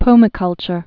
(pōmĭ-kŭlchər)